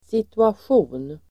Uttal: [situasj'o:n]